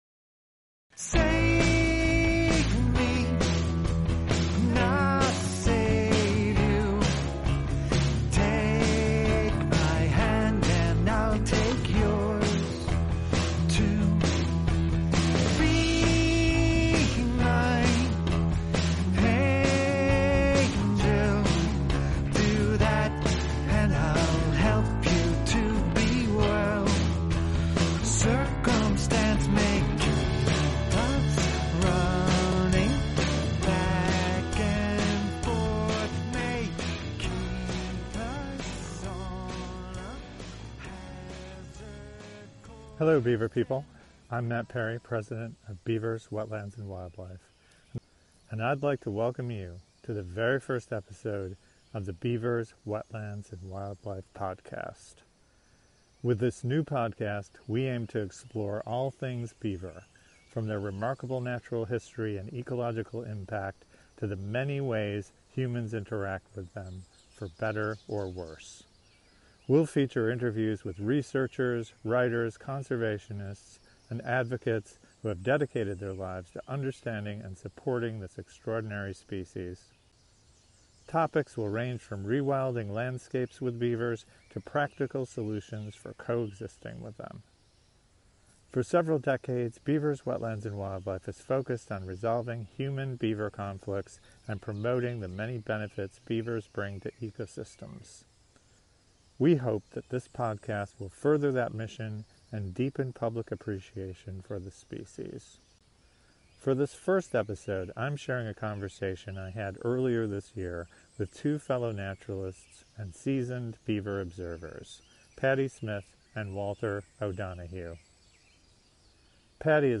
What you’re about to hear is the first recorded session from those meetings. The conversation is unscripted and wide-ranging, a relaxed exchange of stories, field notes, and insights.